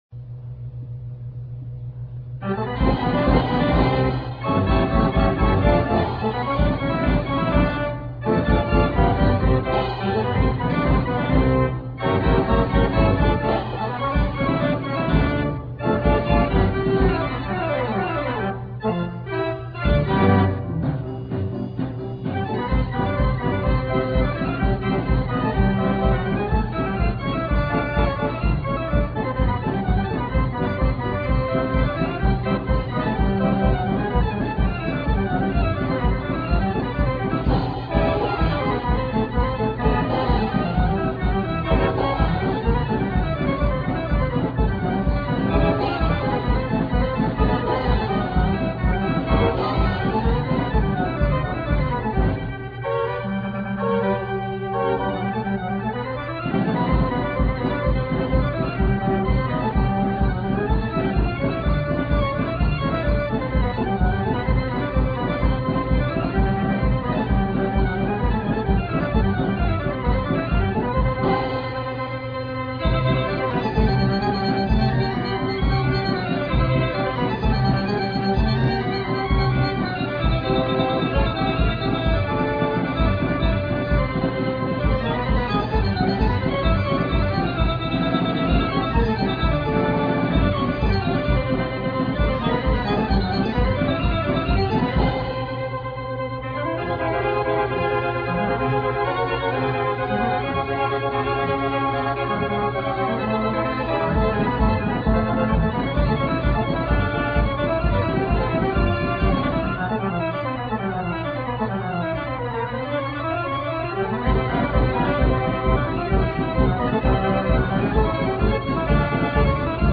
March Hongroise de Concert